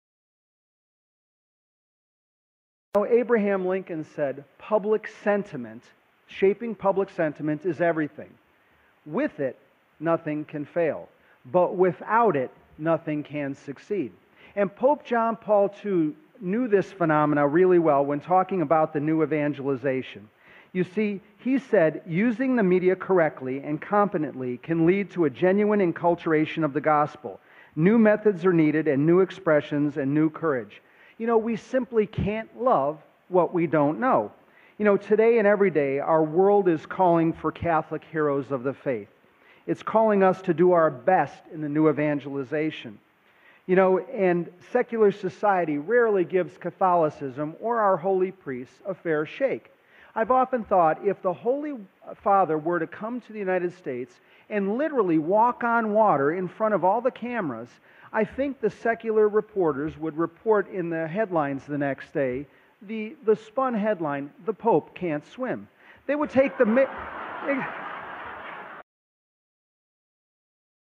1400 Catholics at a Recent Conference!